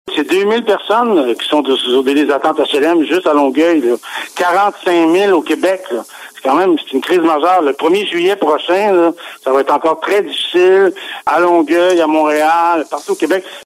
C’est ce que le député de Longueuil – Saint-Hubert a déclaré sur nos ondes ce mercredi.